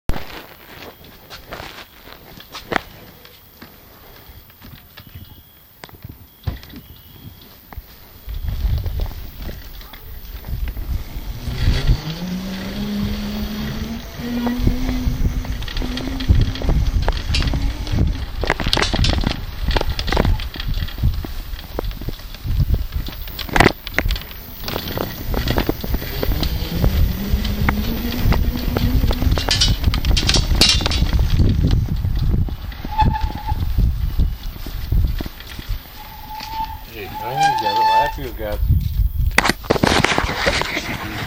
ciao posso chiederti se mi potresti dare una ascoltatina ad un clipaudio? si tratta di una mini registrazione di qualche secondo, riguardante il rumore della motoruota della bici di mio papà, che è una xideko, e volevo capire se era una motorruota gearless o di una geared! se non ho capito male la gearless, è senza ingranaggi, e la geared invece li ha?